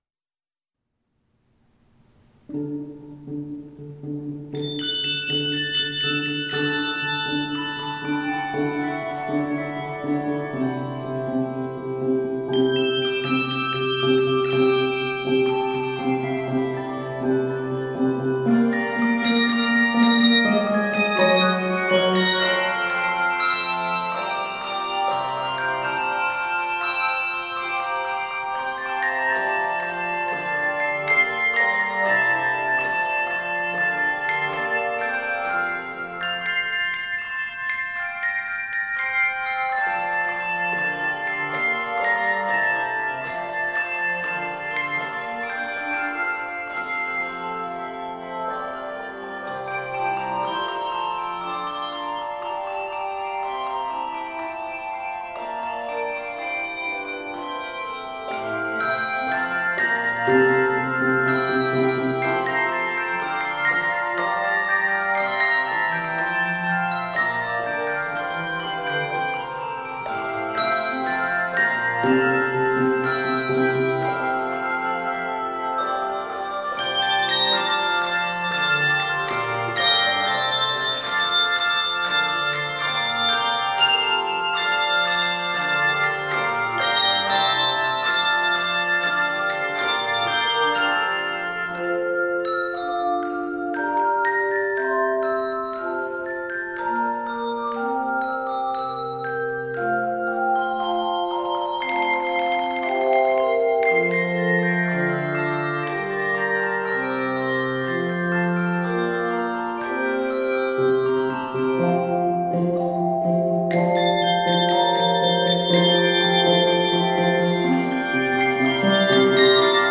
Key of F Major.